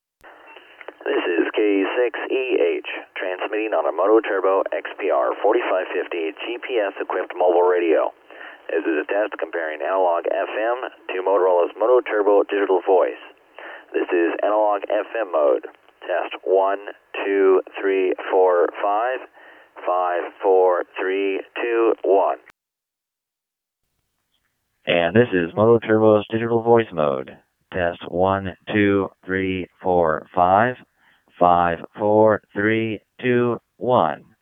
XPR4550 WIDE BAND ANALOG FM vs DV
mototrbo.wav